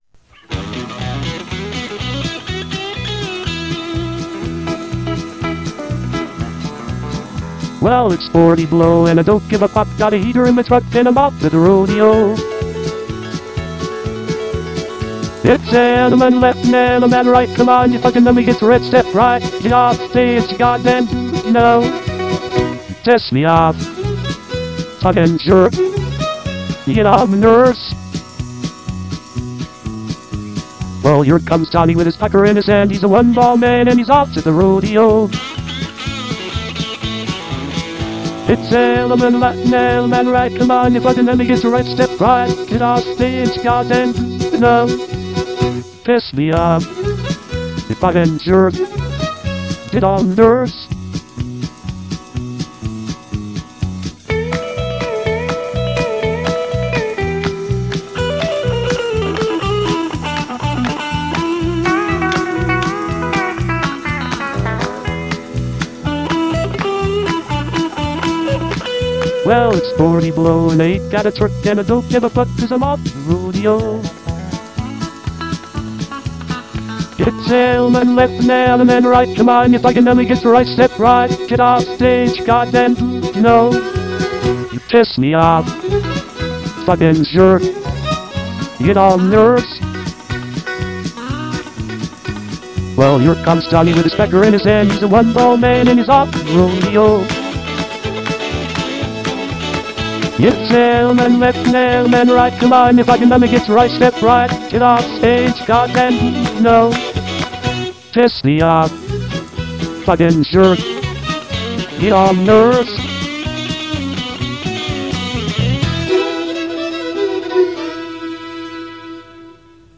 Ya know, your standard AI slop.